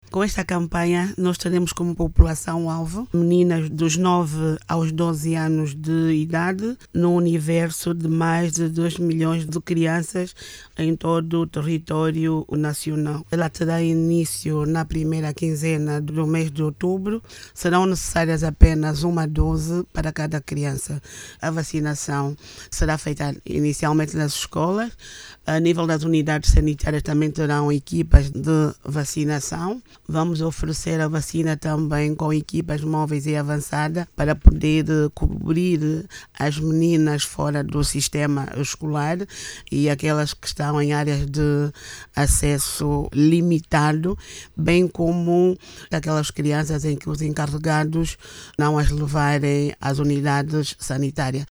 Em declarações à Rádio Nacional de Angola